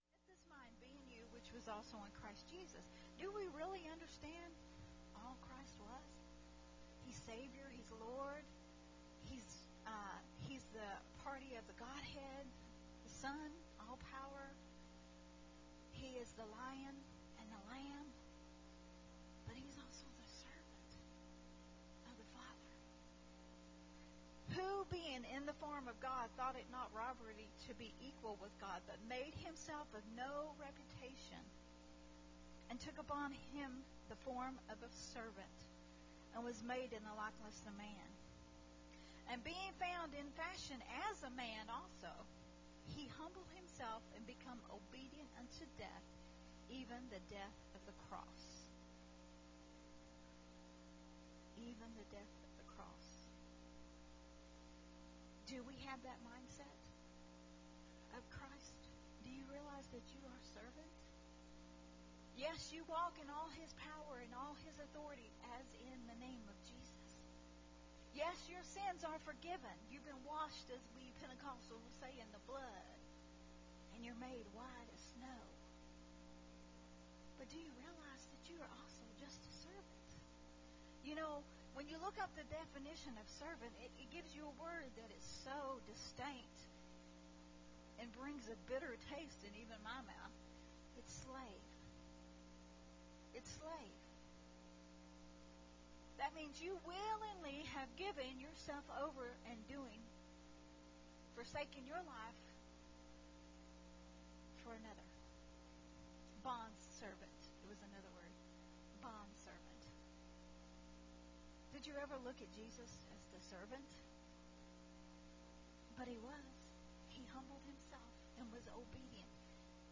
Recorded at Unity Worship Center on 4/18/2022.